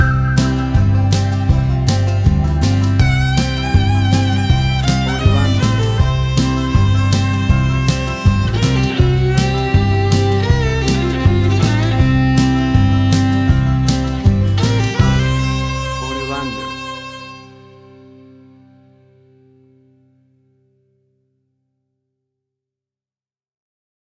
Banjo country music for all country music lovers!
Tempo (BPM): 80